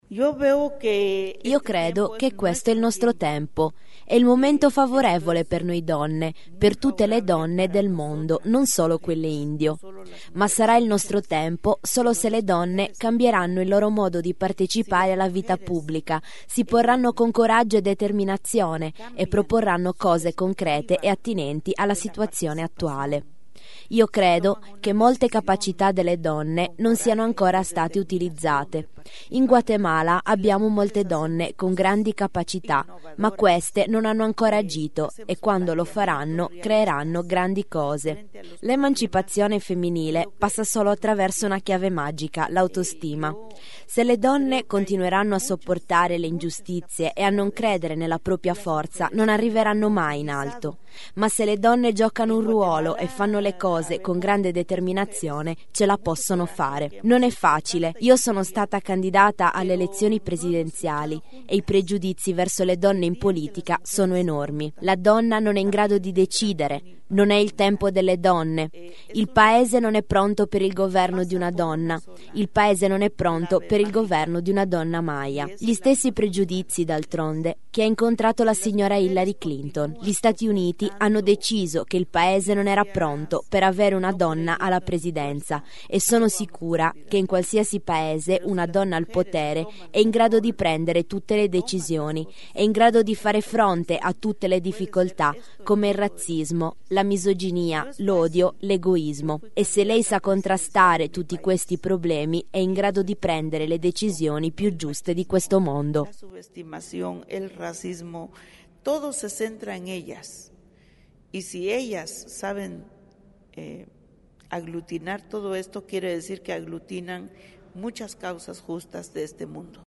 A pochi giorni dalla festa della donna, la Menchù ha sottolineato ai nostri microfoni l’importanza del ruolo femminile nella politica ma anche la grande difficoltà e i pregiudizi che il le donne trovano all’interno delle istituzioni: “Se le donne continuano a sopportare le ingiustizie e non credere alla loro forza non arriveranno mai in alto.